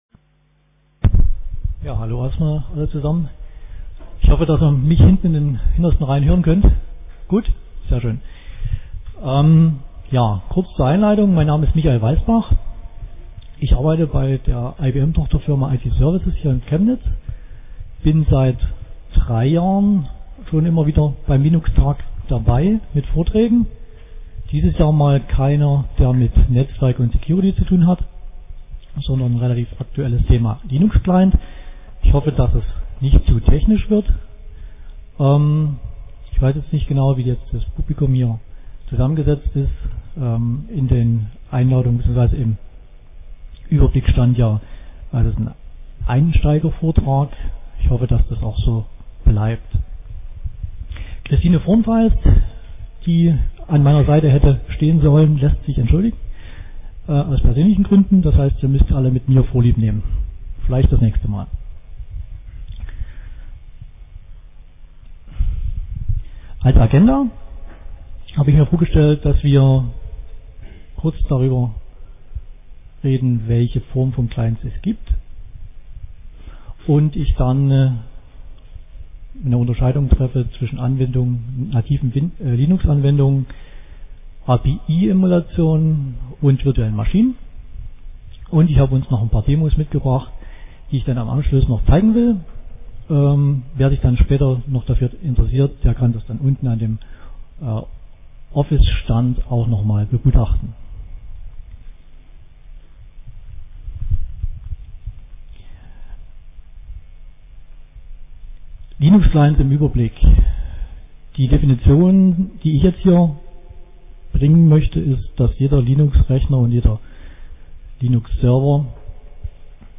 Samstag, 14:00 Uhr im Raum V3 - Anwendungen